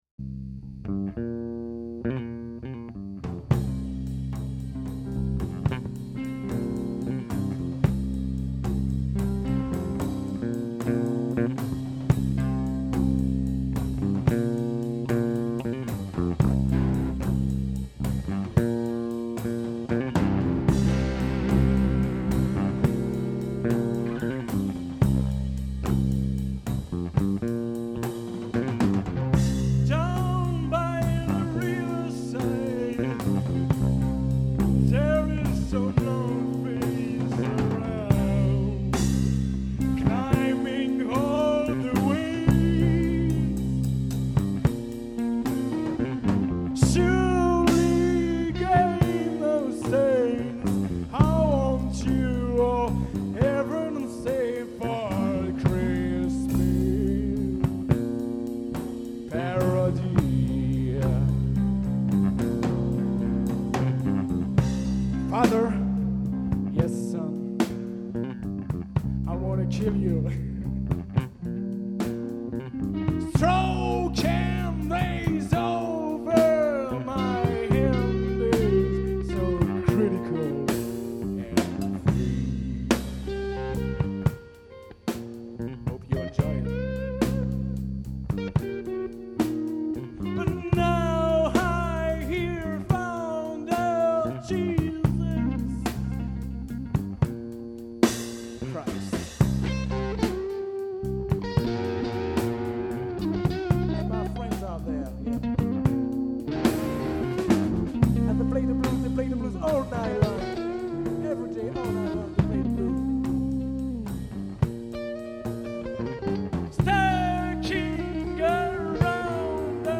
Chant + Basse
Guitares
Drums